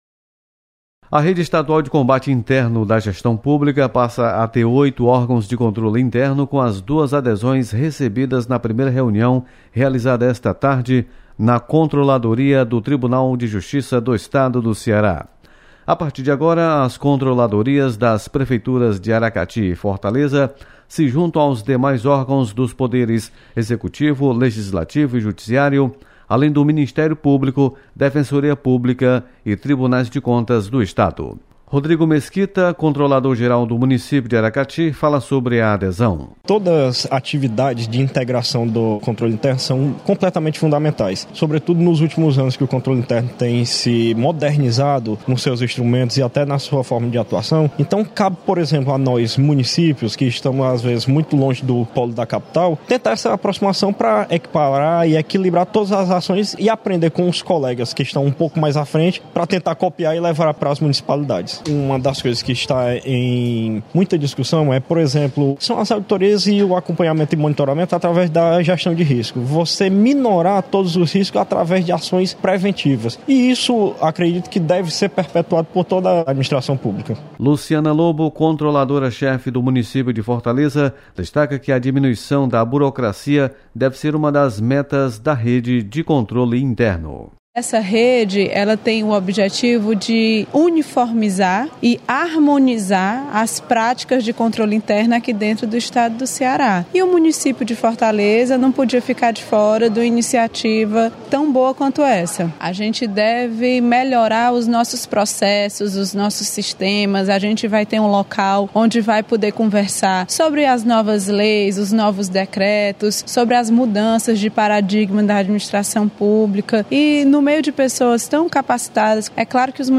Rede Estadual de Controle Interno  ganha mais duas adesões. Repórter